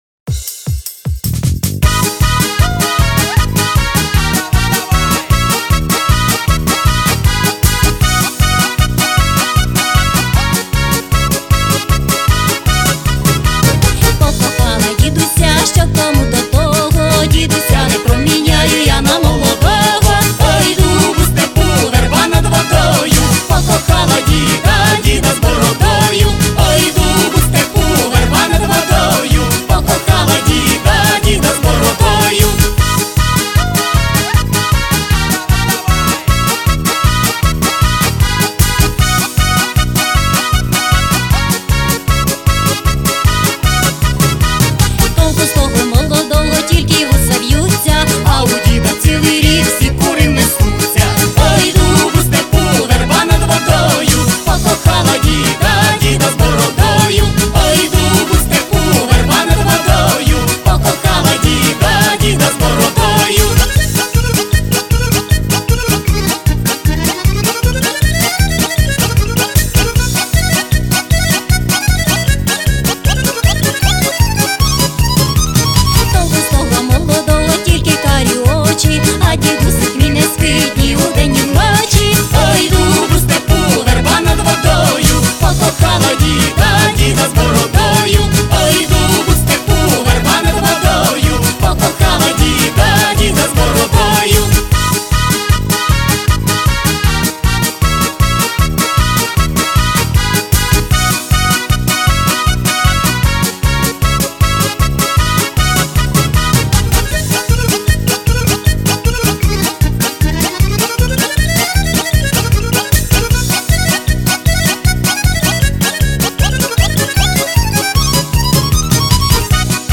Всі мінусовки жанру Pop-Folk
Плюсовий запис